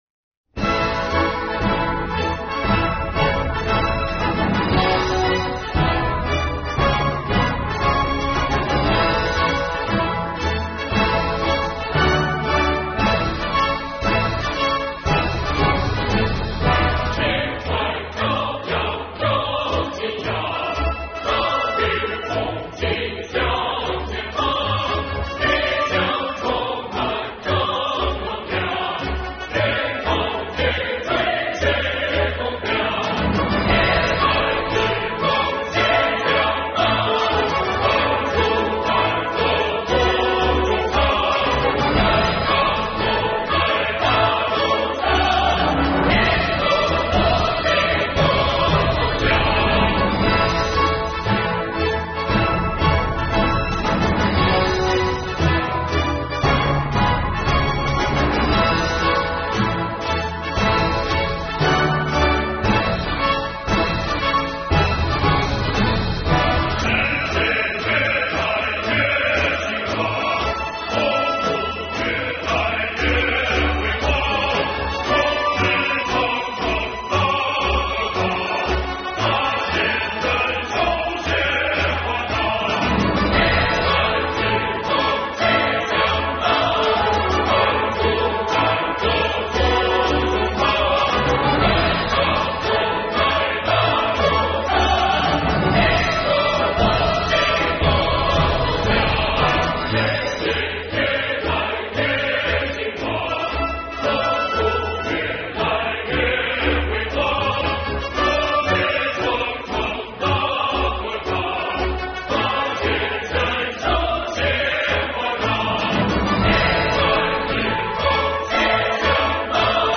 合唱歌曲
是一首合唱作品
以四二拍进行曲风格